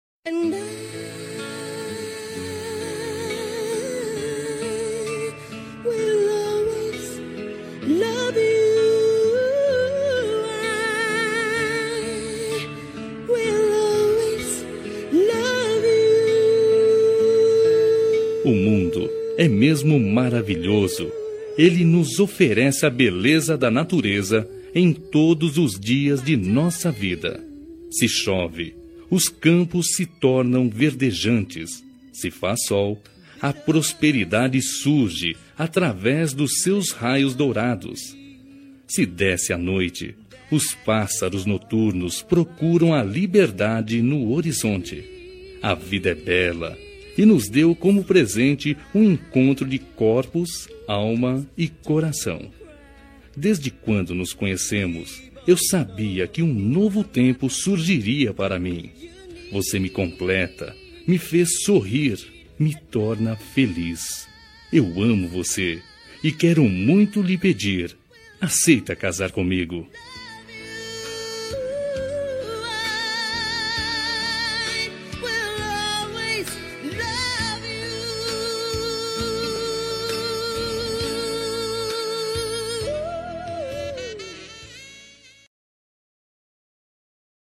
Telemensagem de Pedido – Voz Masculina – Cód: 041714 – Quer Casar